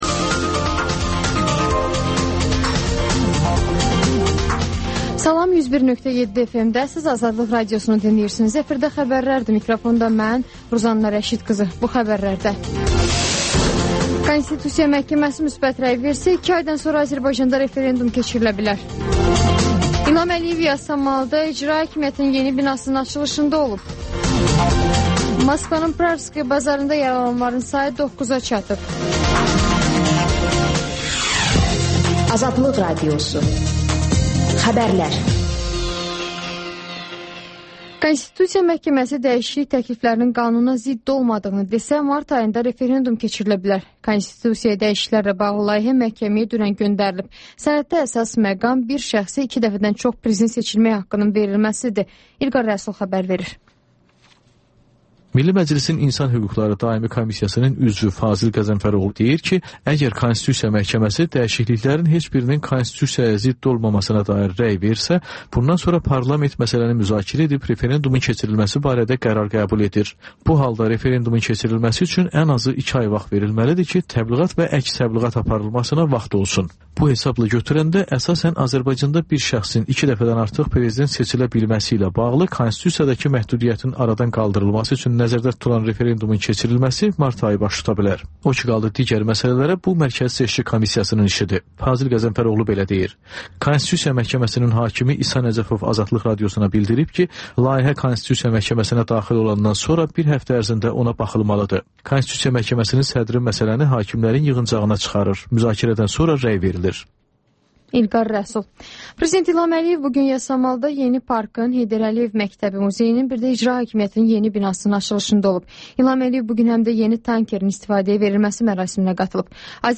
Xəbərlər və XÜSUSİ REPORTAJ